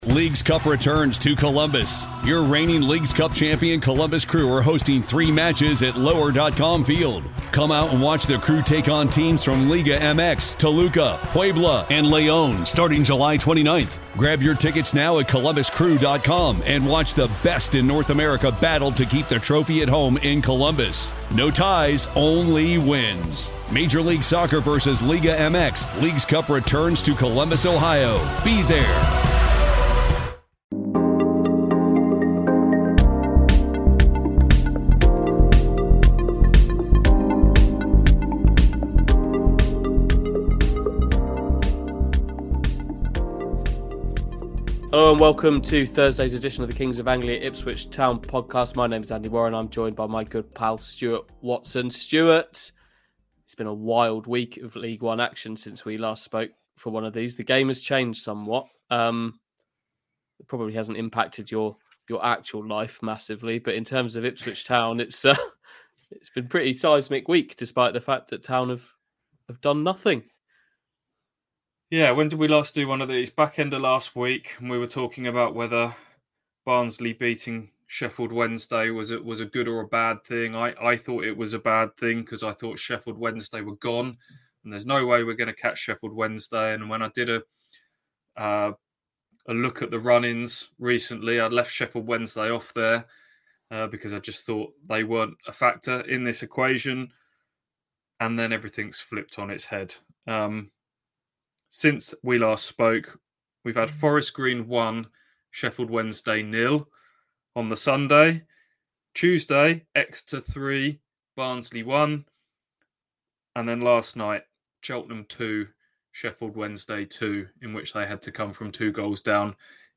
Plus, a special guest joins us midway through the pod to give us an update.